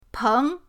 peng2.mp3